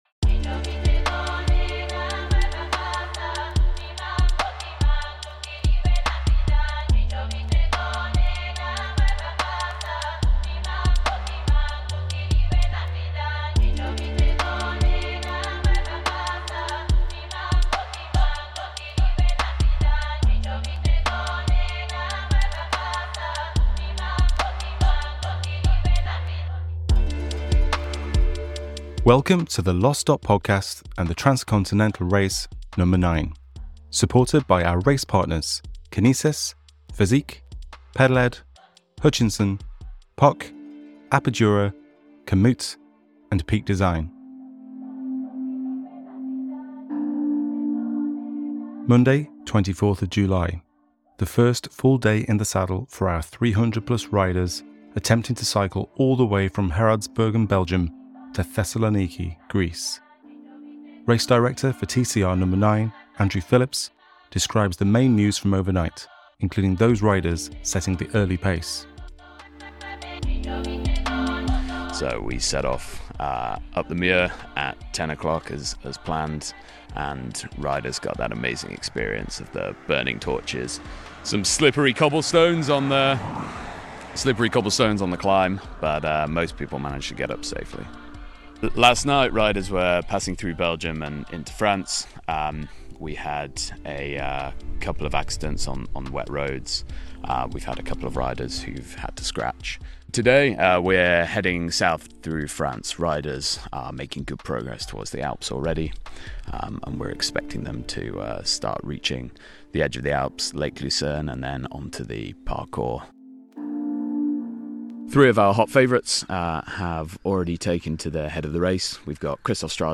we hear from riders early on